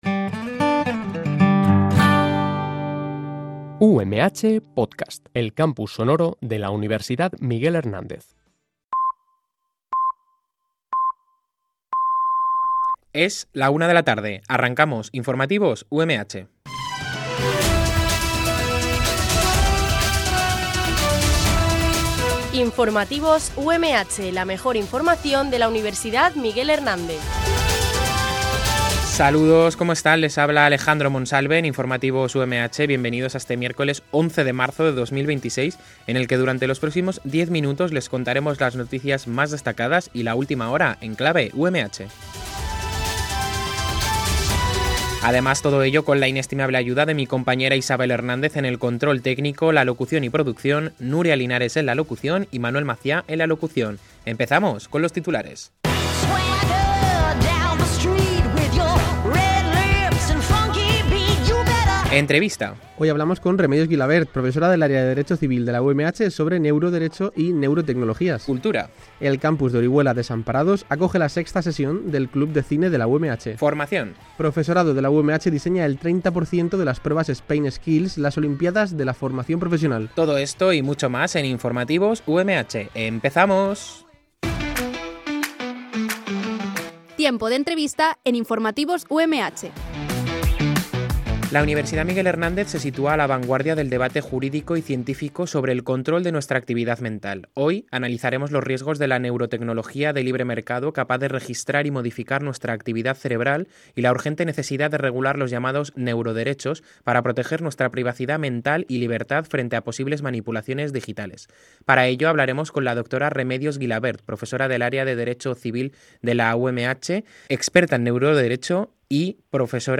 Este programa de noticias se emite en directo, de lunes a viernes, en horario de 13.00 a 13.10 h